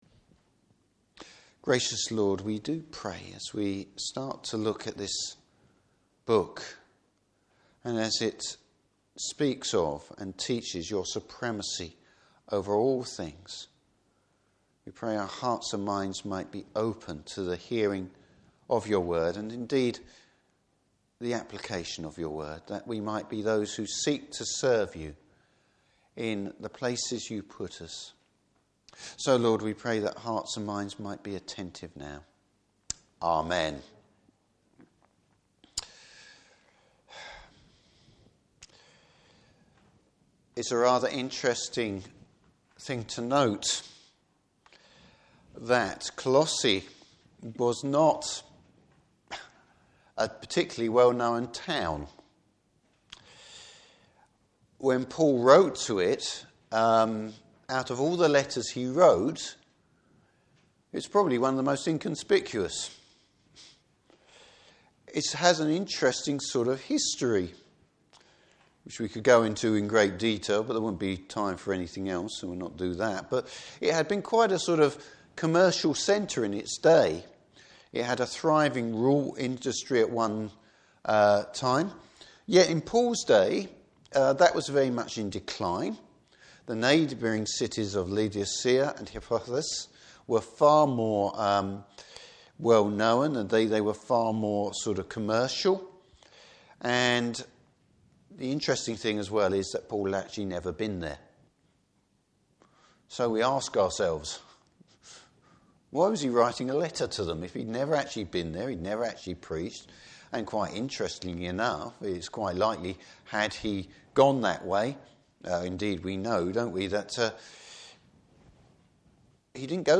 Service Type: Morning Service The identity of the Lord’s people.